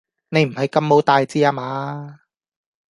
Гонконгский 763